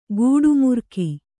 ♪ gūḍu murki